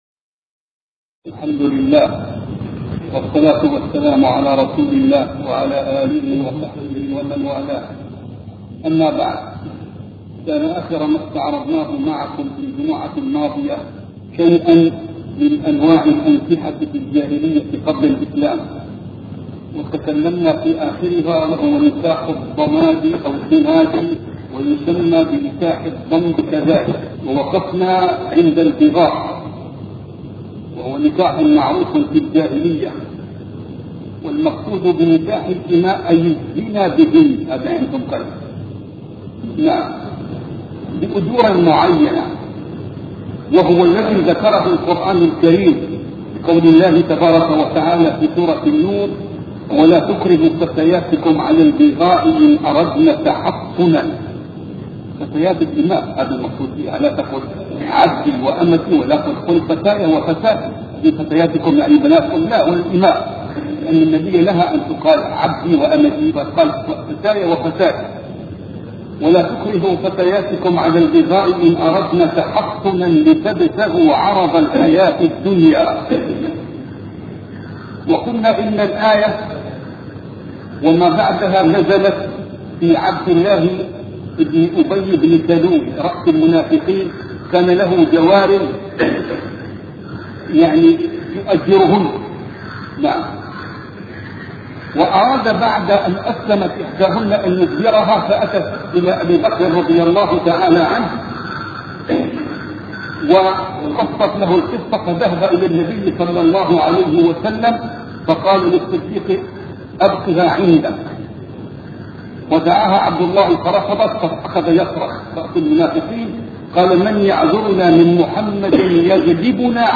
سلسلة محاطرات